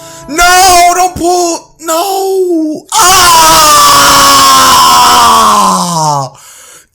failsound.wav